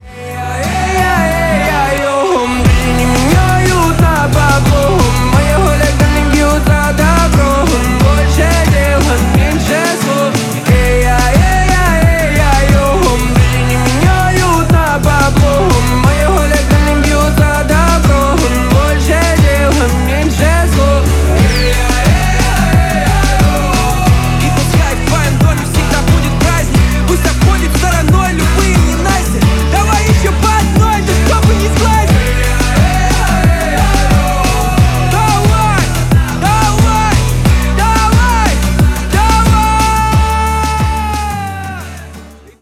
• Качество: 320, Stereo
гитара
громкие
веселые